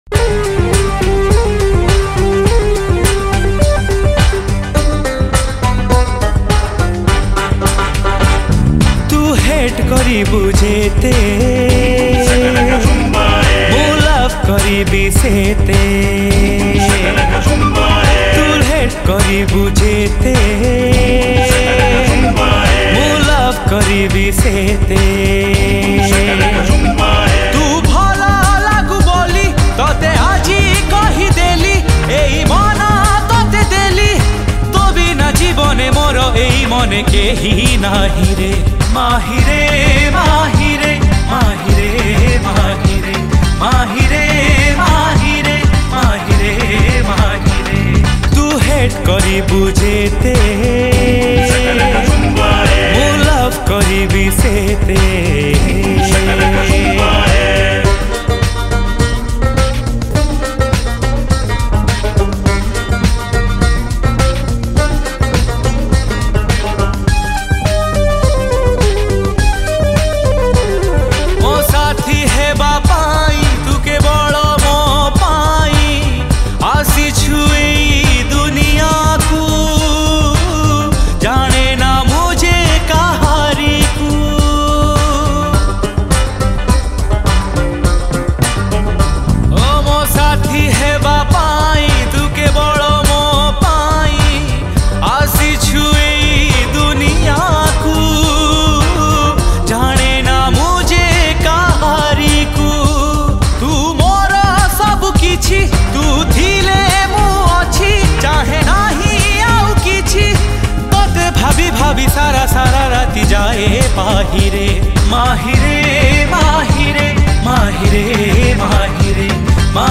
Romantic Songs
Superhit Odia Songs